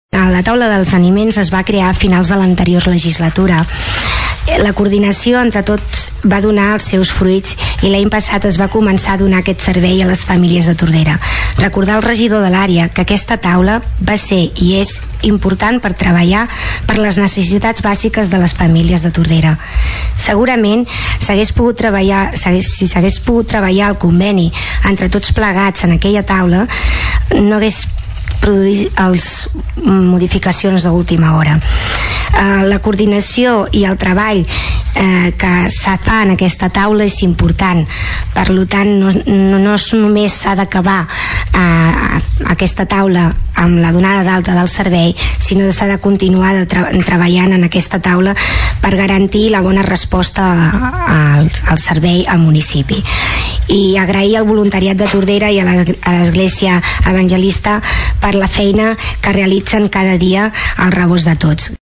La regidora del PSC, Toñi Garcia, va agrair la feina de les entitats i els tècnics de l’Ajuntament que han participat a la Taula de Treball.